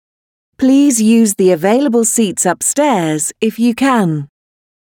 Bus-Infotainment--IBus-/assets/audio/manual_announcements/seatsupstairs.mp3 at 2846a061cc59682eb53fec84b23d58a01b67df5d
seatsupstairs.mp3